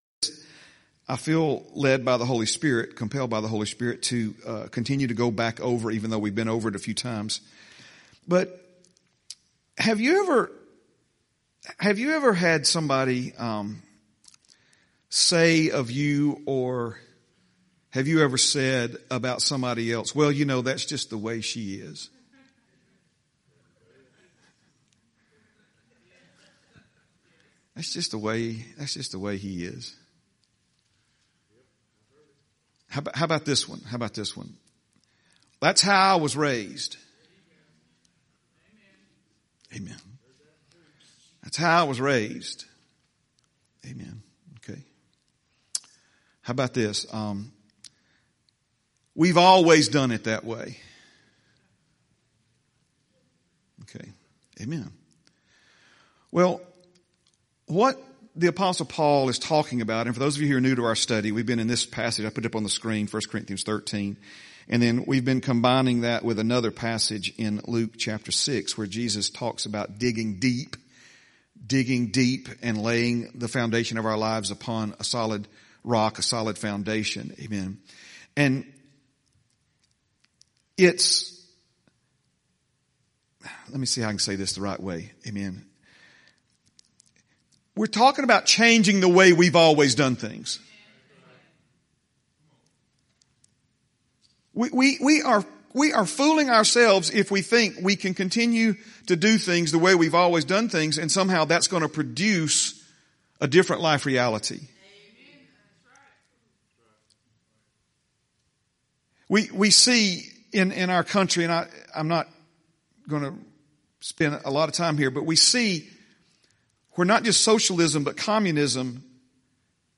11/16/25 Sunday - Sunday Morning Message